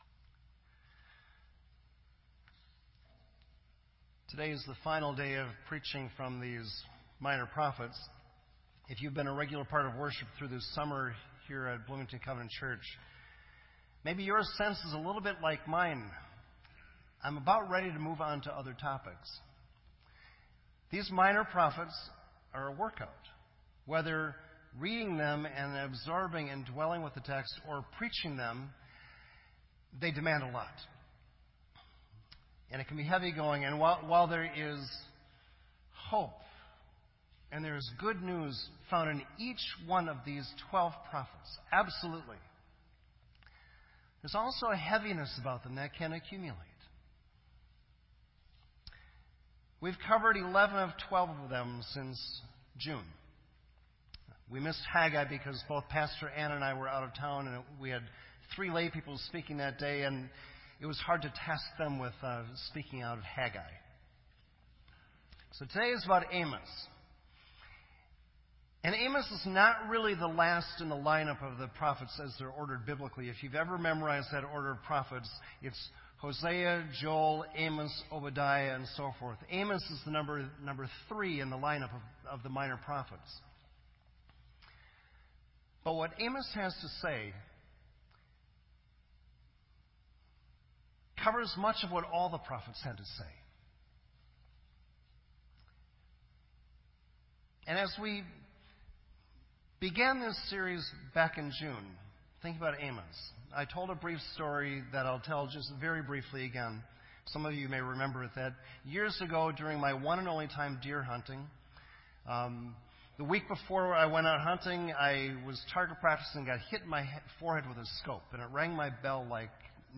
This entry was posted in Sermon Audio on September 5